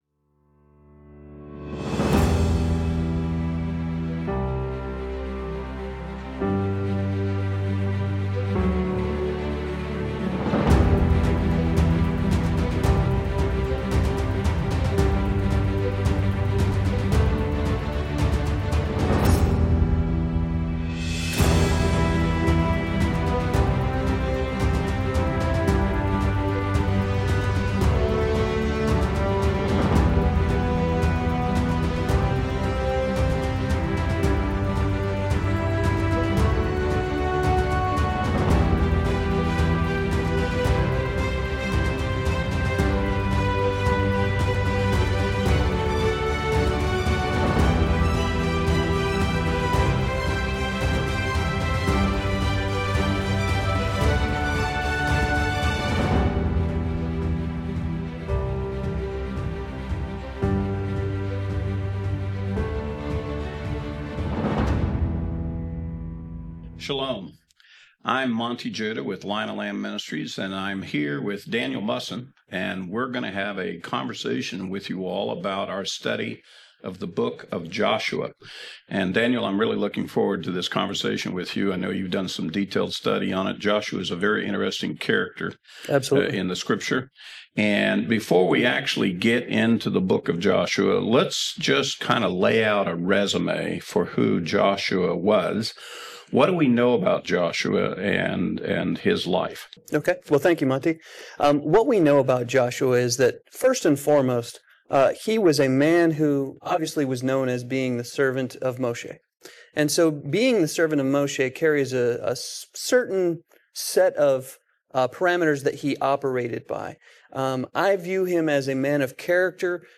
This in-depth discussion examines Joshua's patience, faithfulness, and transformation from Hoshea to Yehoshua, revealing key insights into one of the Bible's most significant leaders.